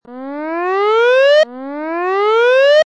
Descarga de Sonidos mp3 Gratis: sirena 11.
descargar sonido mp3 sirena 11